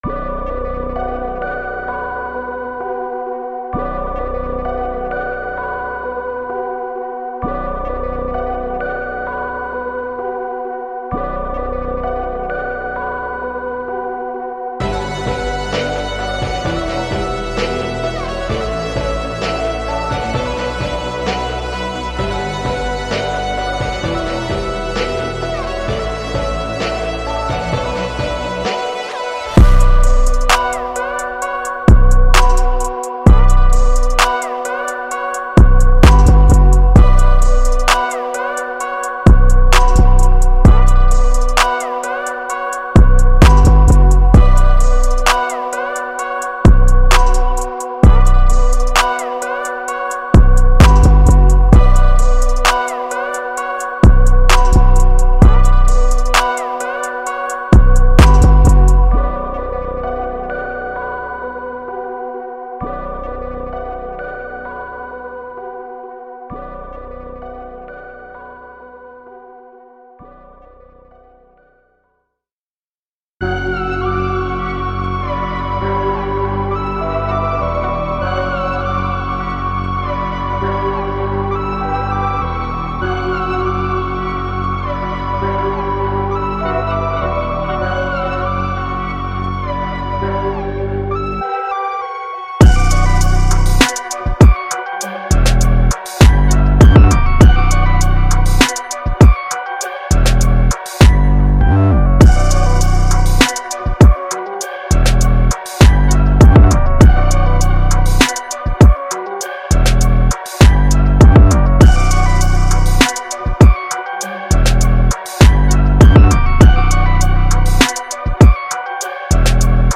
Hip HopTrap
合成器One Shot，循环，MIDI等的高级精选！
所有这些循环都是使用传奇的一张镜头制作的